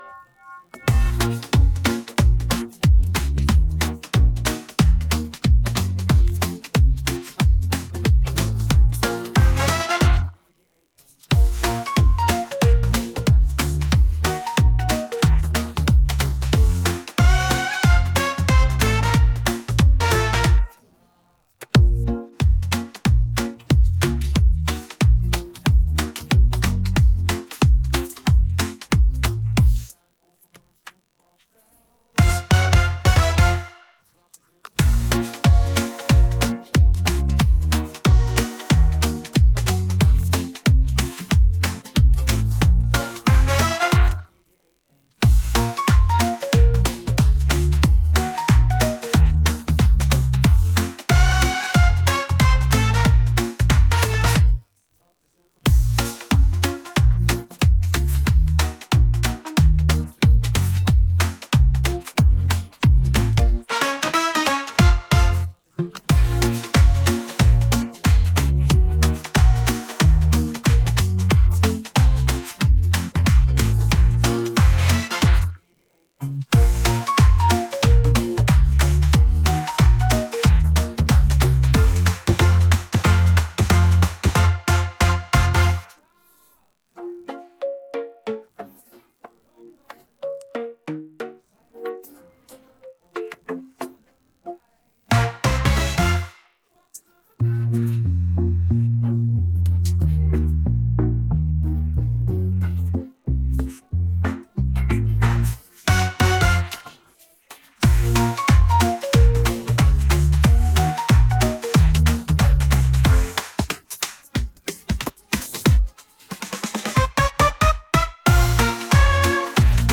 karaokeversie van het liedje om luidkeels mee te zingen.